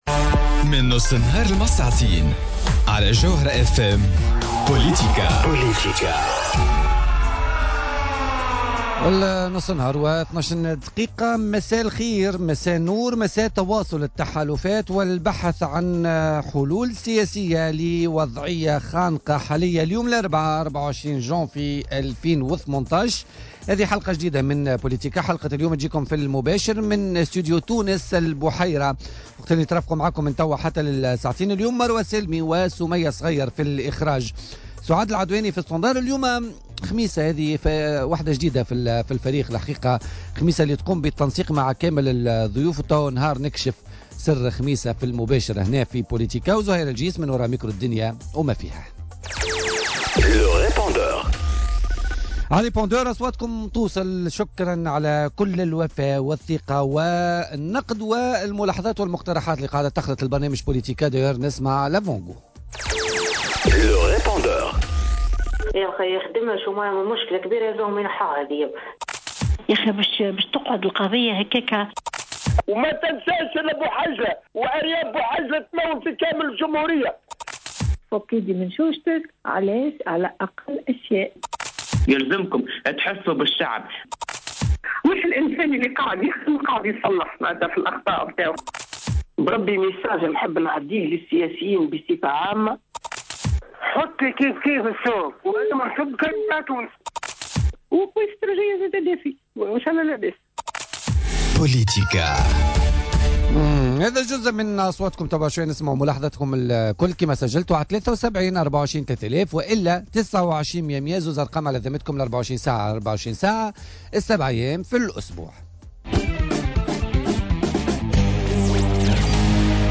ياسين ابراهيم رئيس حزب أفاق تونس ضيف بوليتيكا